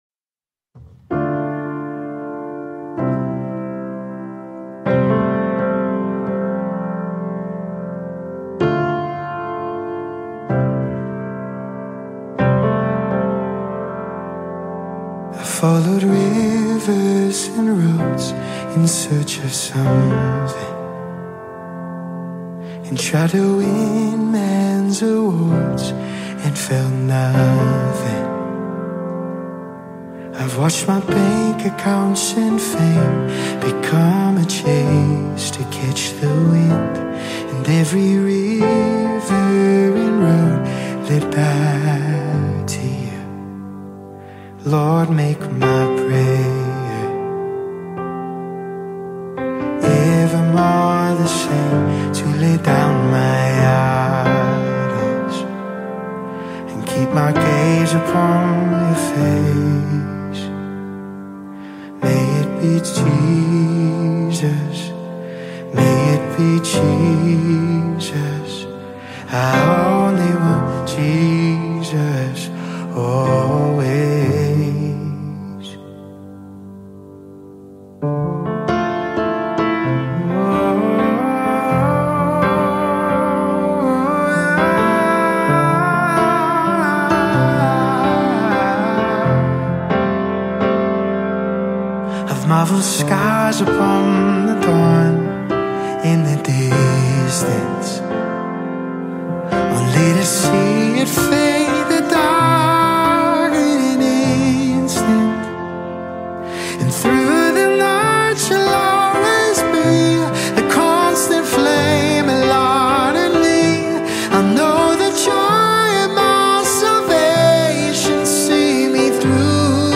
Arizona worship team
this beautiful track is both hopeful and powerful.